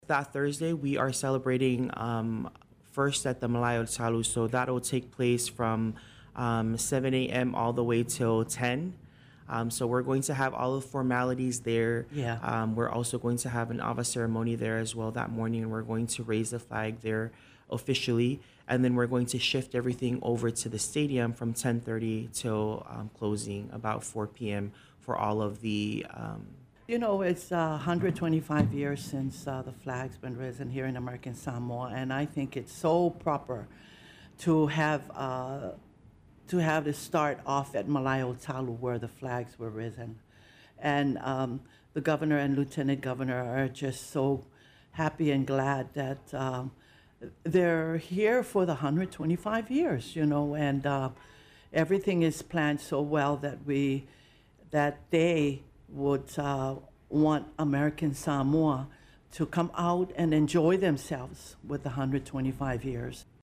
on 93 KHJ’s Morning Show on Tuesday